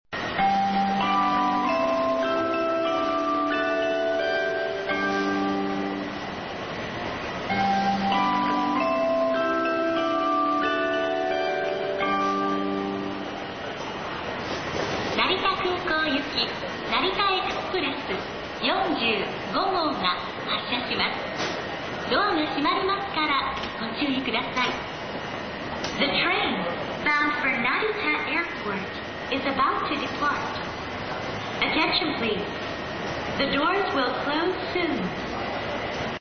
kuukou-dai2biru-downhassya.mp3